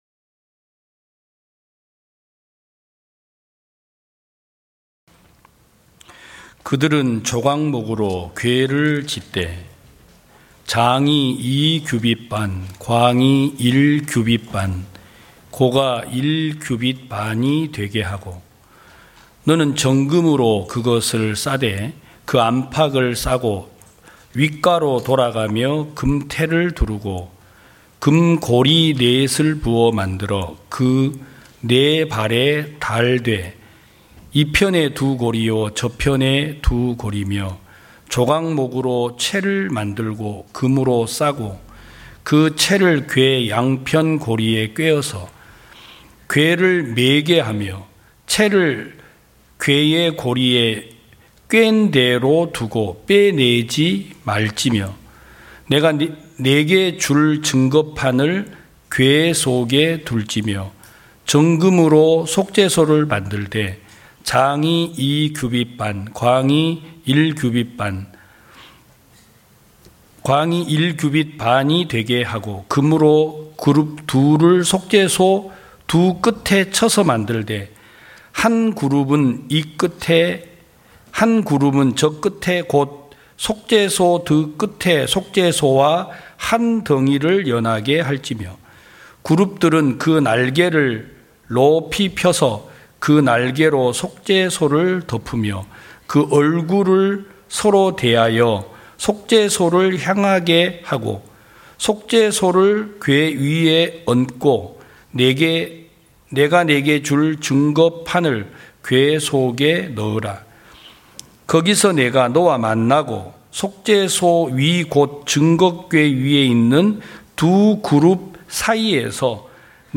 2022년 11월 13일 기쁜소식부산대연교회 주일오전예배
성도들이 모두 교회에 모여 말씀을 듣는 주일 예배의 설교는, 한 주간 우리 마음을 채웠던 생각을 내려두고 하나님의 말씀으로 가득 채우는 시간입니다.